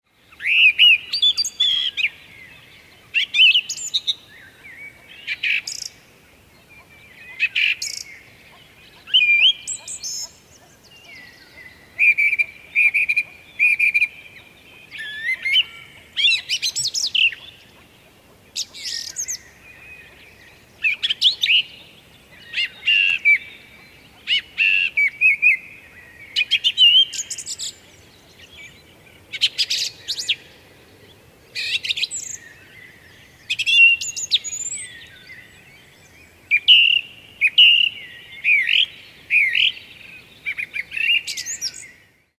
Digiloto birdsong game - learn common Estonian bird sounds through play.
laulur2stas.ogg